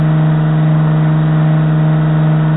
320flaps.wav